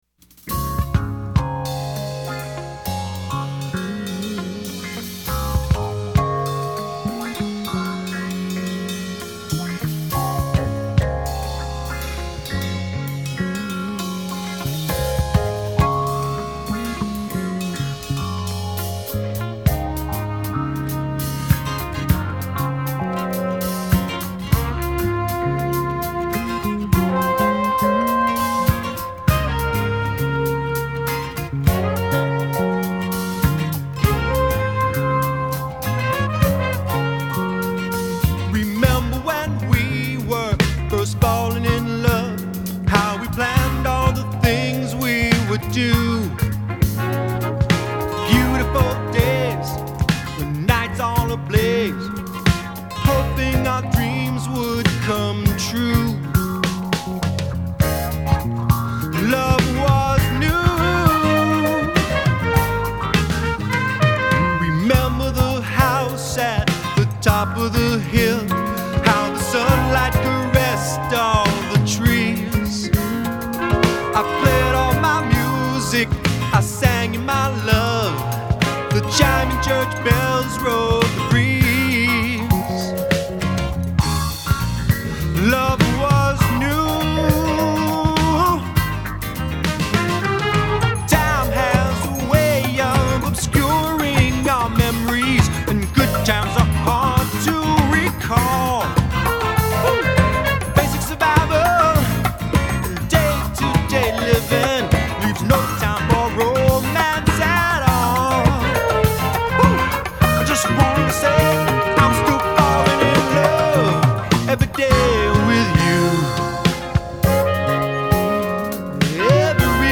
Медляки